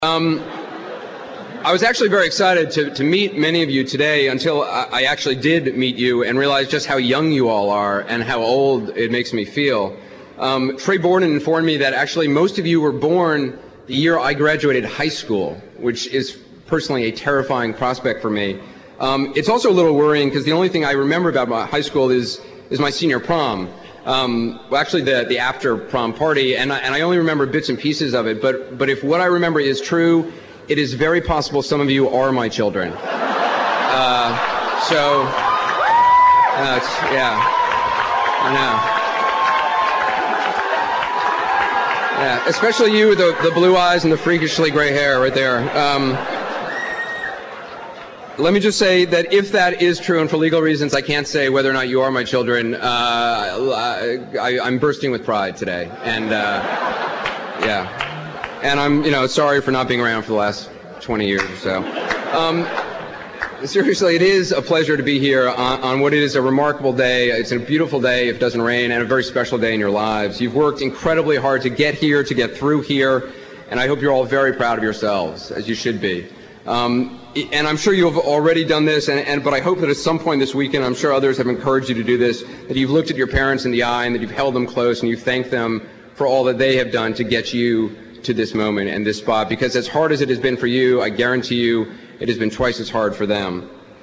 名人励志英语演讲 第123期:追随你的幸福 倾听你的心声(2) 听力文件下载—在线英语听力室